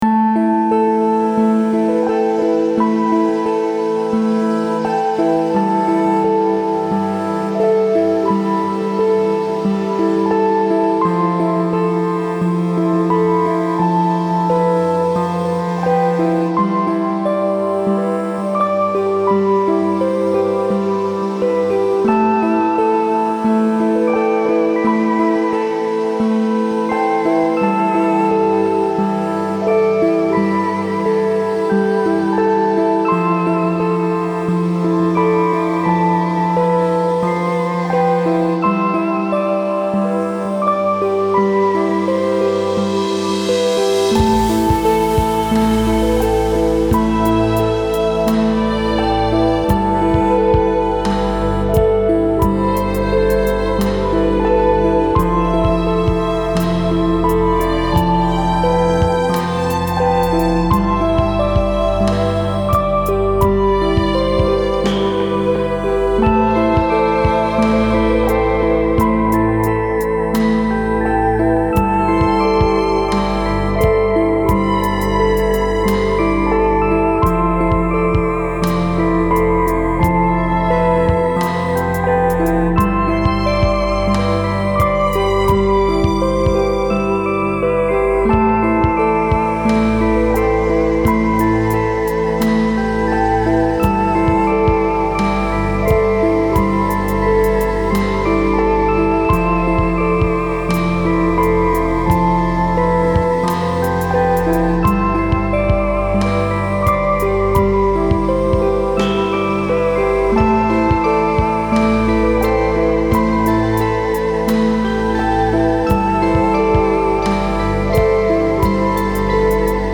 Feels like another space exploration type of piece, but I think it could be used for all kinds of stuff.